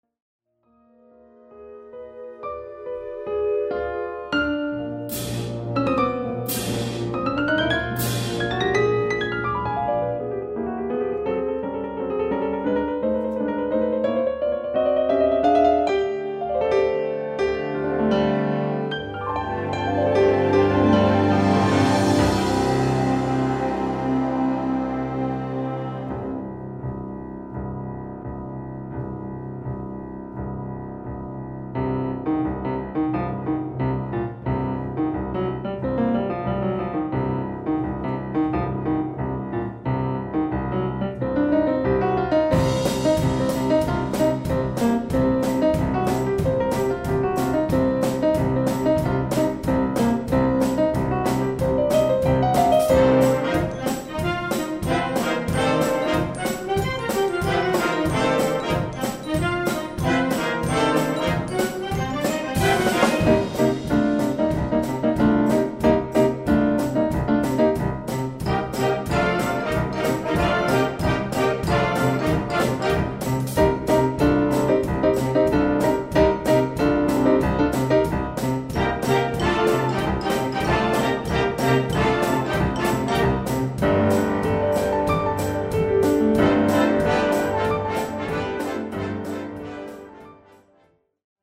Gattung: Klavier und Blasorchester
Besetzung: Blasorchester